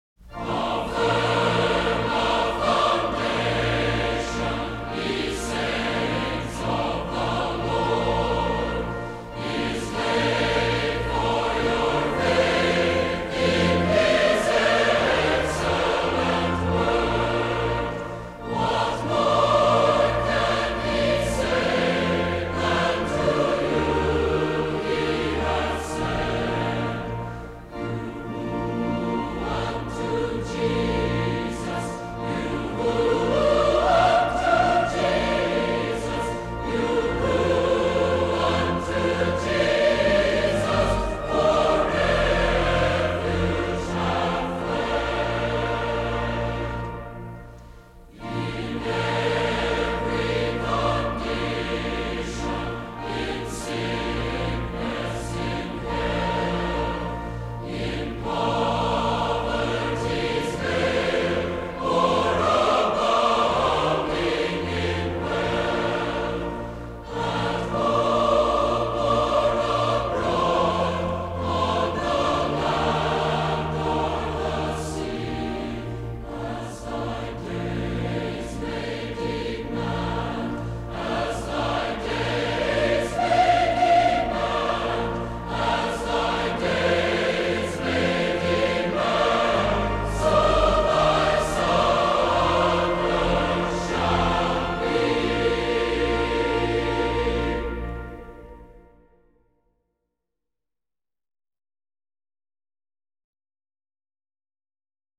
Listen: Mormon Tabernacle Choir (1960)
09-how-firm-a-foundation-voice.mp3